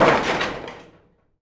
metal_sheet_impacts_04.wav